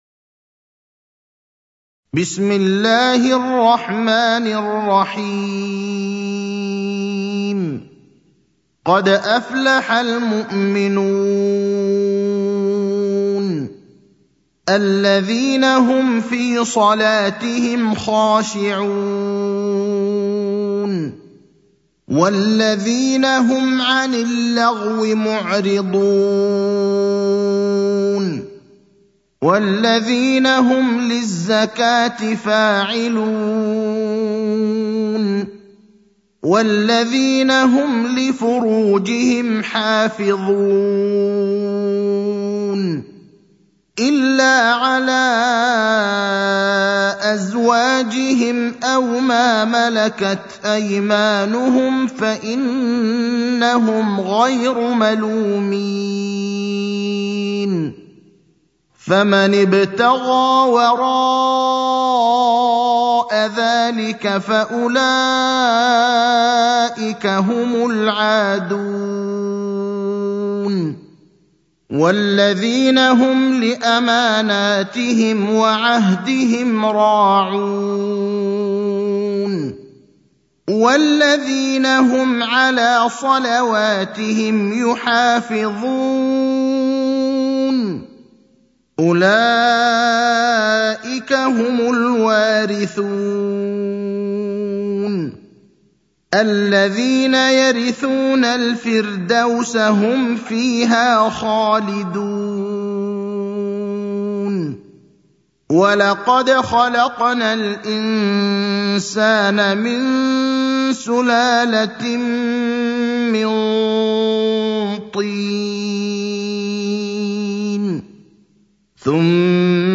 المكان: المسجد النبوي الشيخ: فضيلة الشيخ إبراهيم الأخضر فضيلة الشيخ إبراهيم الأخضر سورة المؤمنون